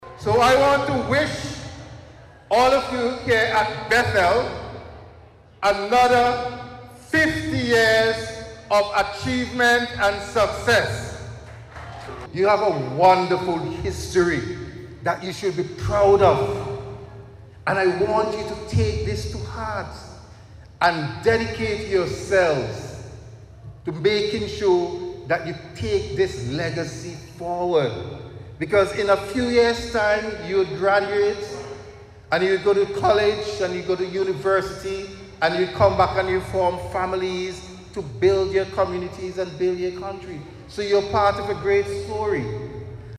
The Bethel High School hosted an Anniversary Service today as part of its 50th anniversary celebration.
The service was held at the school grounds at Campden Park with the theme – Rooted in Dedication and Discipline – Rising to Excellence – Our Fifty Year Journey.